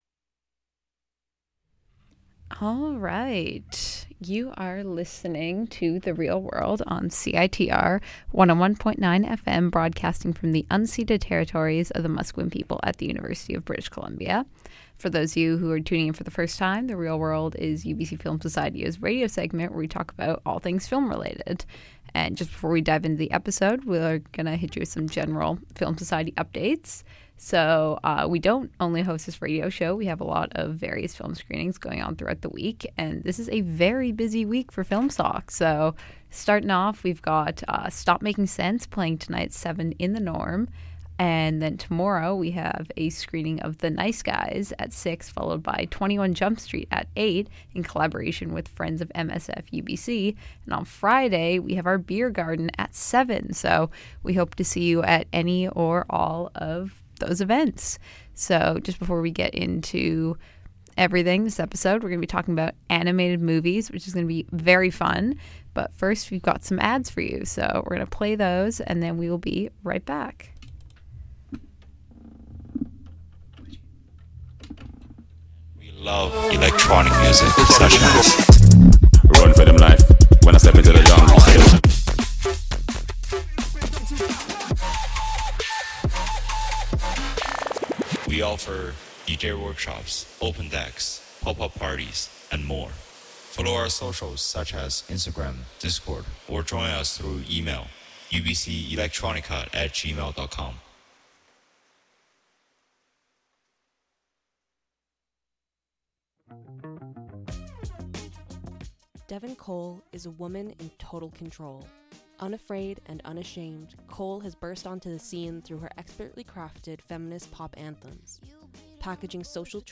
Listen in to hear them discuss The Nightmare Before Christmas, Howl's Moving Castle, and Spider-Man: Into the Spider-Verse, and what makes these films so fun to watch and creatively inspiring.